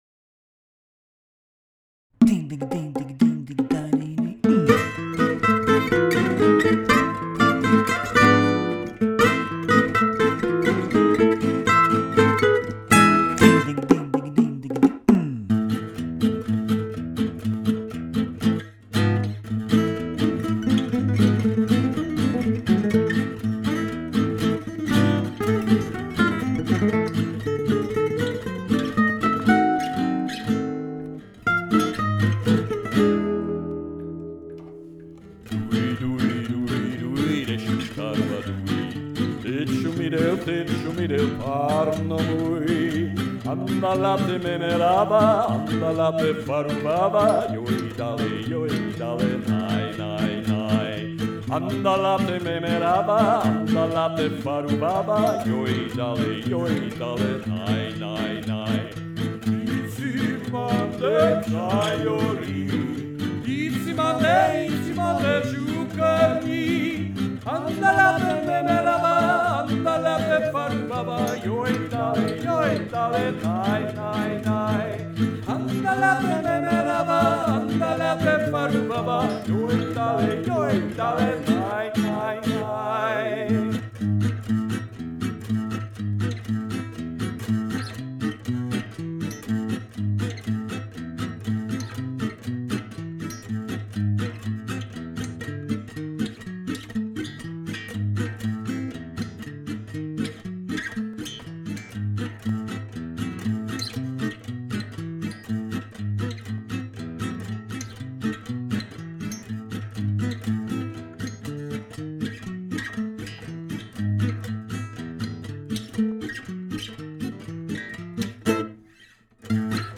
Put some reverb on the vocals that seems unobtrusive.
Walking rhythm guitar should sound better too, before it was doing some weird phasing sound.
Meanwhile I did more tweaking and fixes (only in the 9/8 intro) in the last version and re-uploaded above...